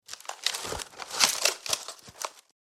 Firewood-kindling-movement.mp3